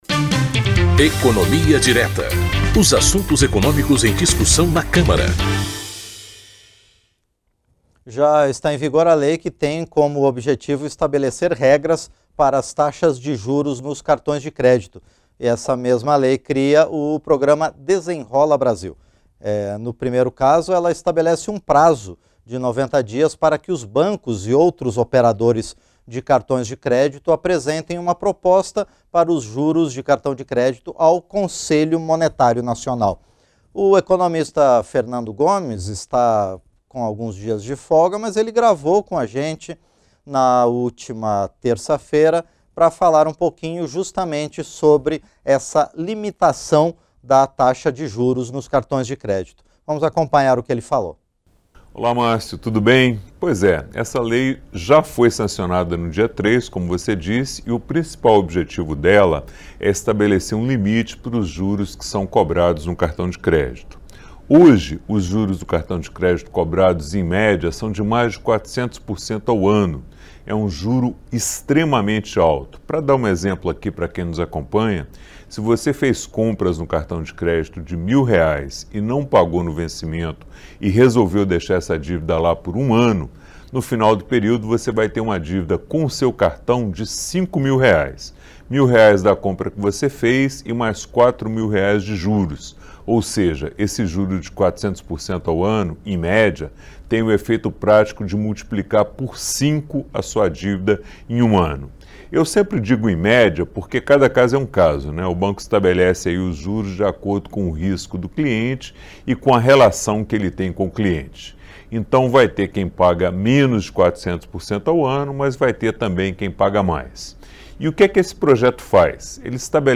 Programas da Rádio Câmara